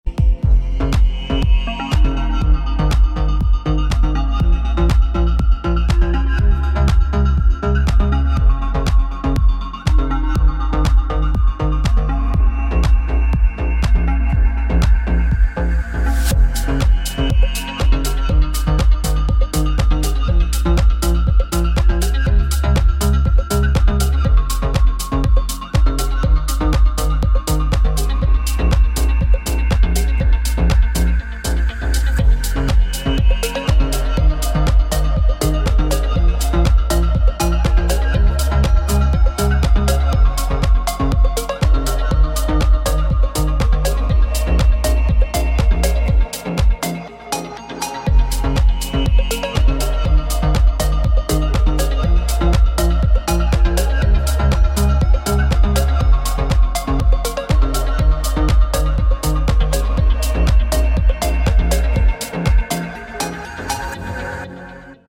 • Качество: 192, Stereo
мелодичные
EDM
без слов
Стиль: deep house